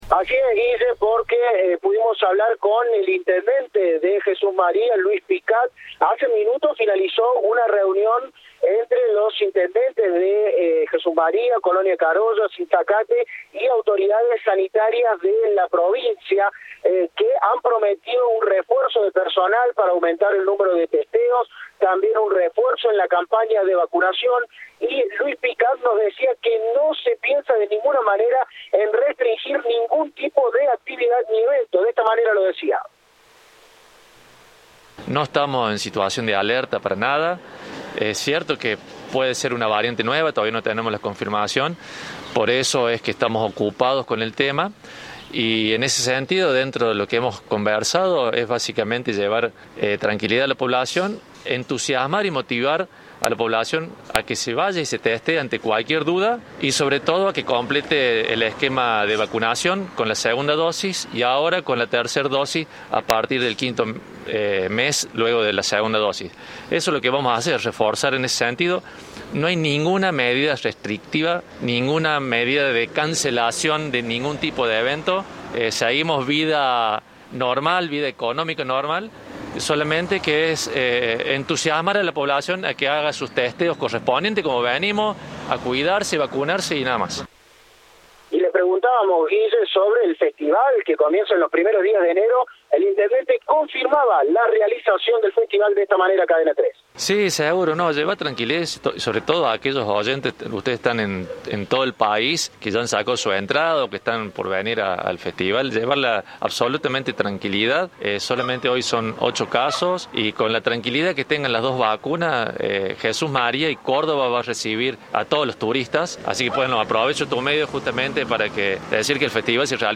Luis Albino Picat, intendente de Jesús María, confirmó en Cadena 3 que el Festival de Doma y Folclore se llevará adelante a pesar del brote de coronavirus.